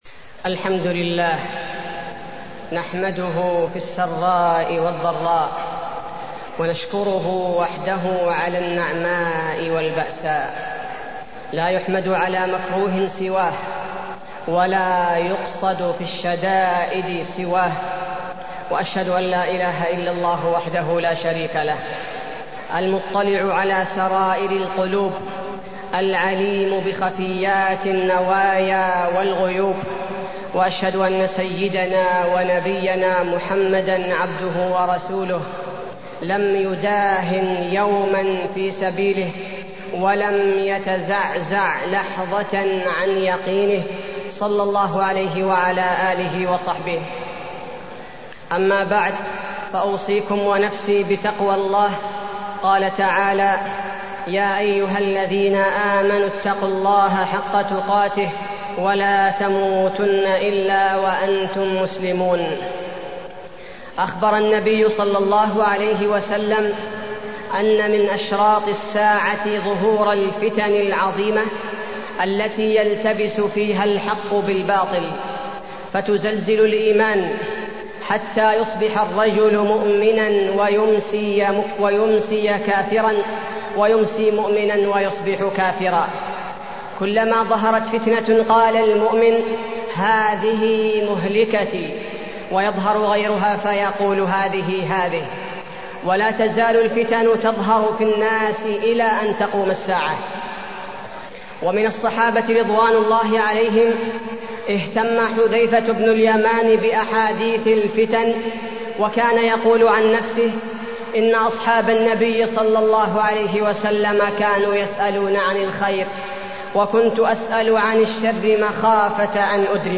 تاريخ النشر ٢٤ شعبان ١٤٢٢ هـ المكان: المسجد النبوي الشيخ: فضيلة الشيخ عبدالباري الثبيتي فضيلة الشيخ عبدالباري الثبيتي الفتن